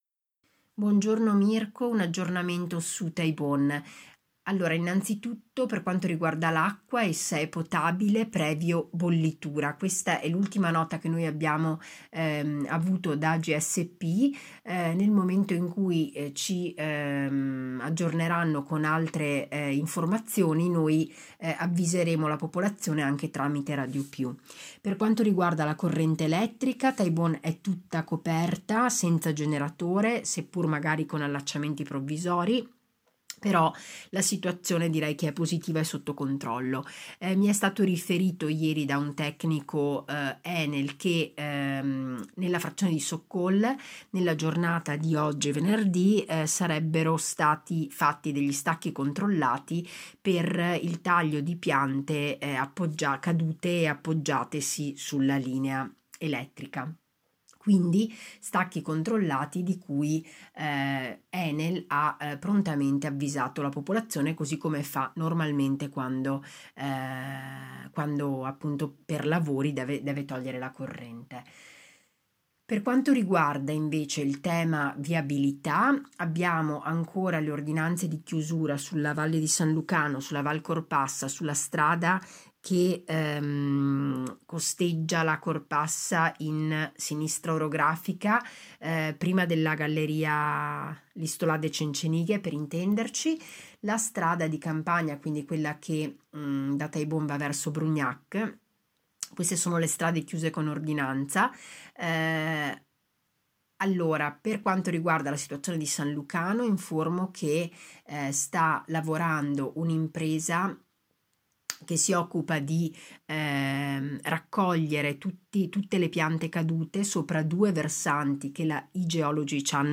TAIBON, IL PUNTO CON IL SINDACO SILVIA TORMEN